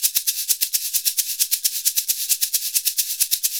Index of /90_sSampleCDs/Univers Sons - Basicussions/11-SHAKER133